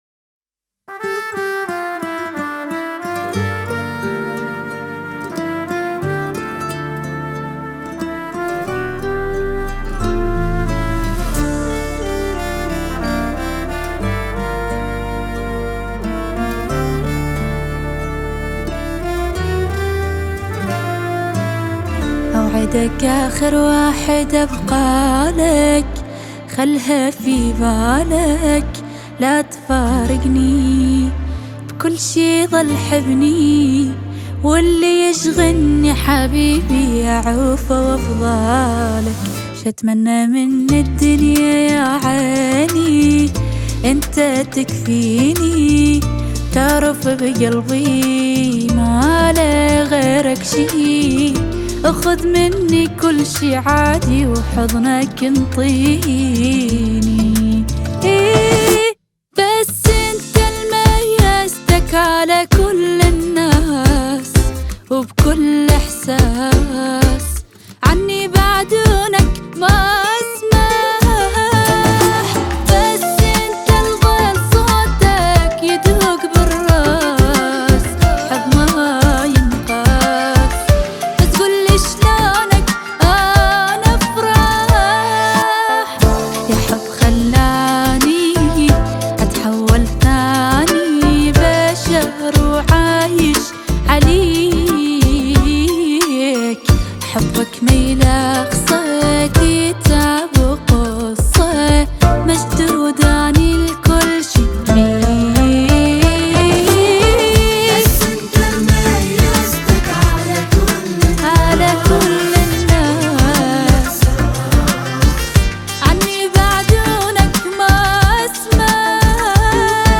إسم القسم : اغاني عراقية